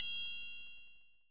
OPEN TRI 2.wav